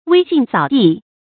威信掃地 注音： ㄨㄟ ㄒㄧㄣˋ ㄙㄠˇ ㄉㄧˋ 讀音讀法： 意思解釋： 威信：威望和信譽。